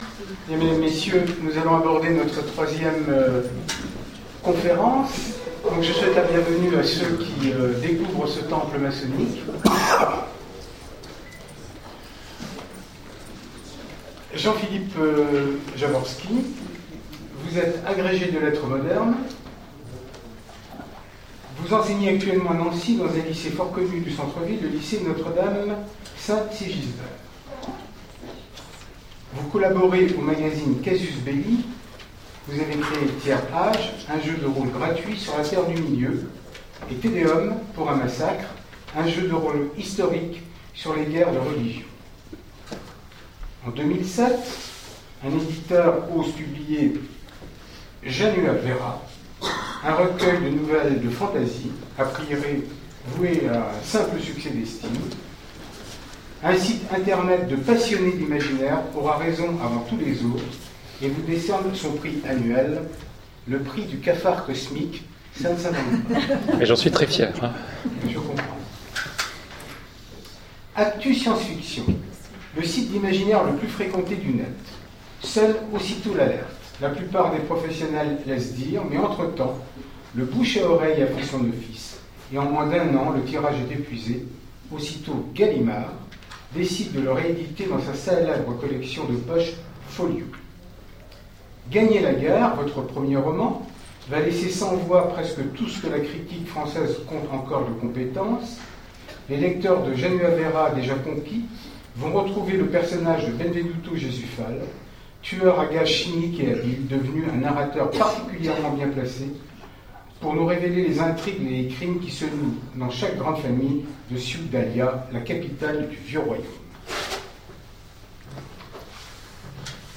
Imaginales 2015 : Conférence Evasion et écriture mytho poétique